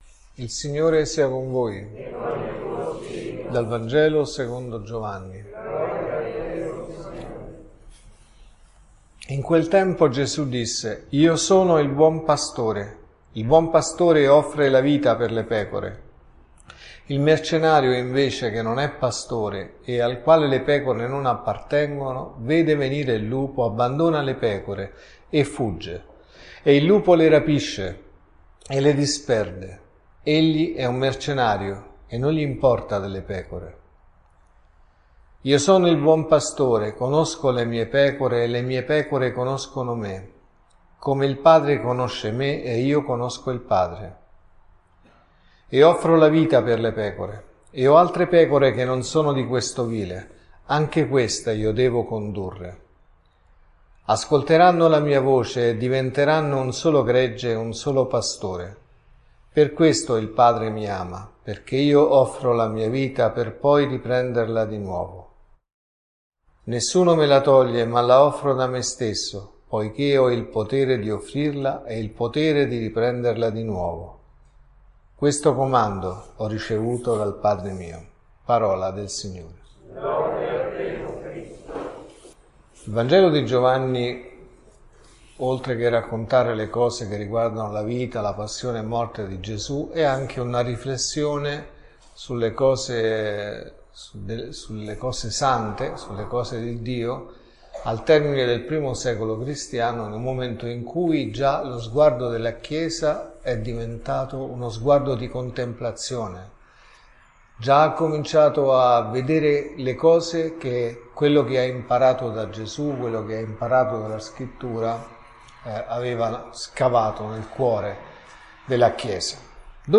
Il buon pastore dà la propria vita per le pecore.(Messa del Mattino e della Sera)
Omelie